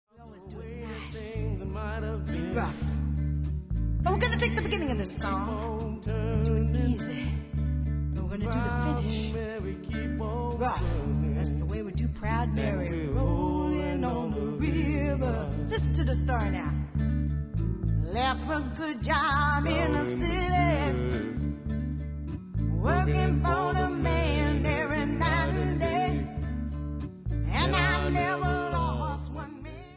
w/vocal